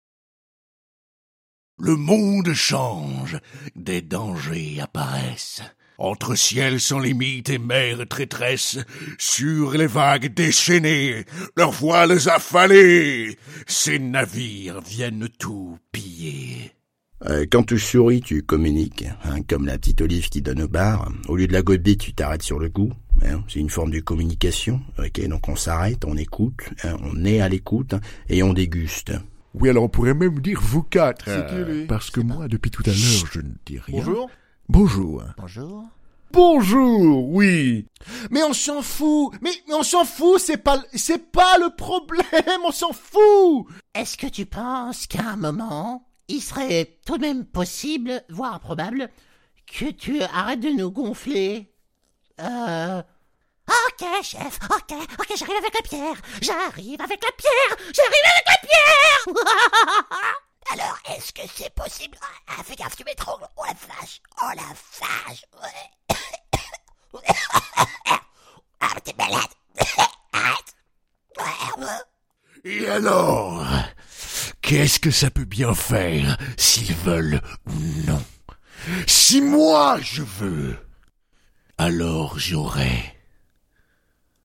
Voix-Off Personnages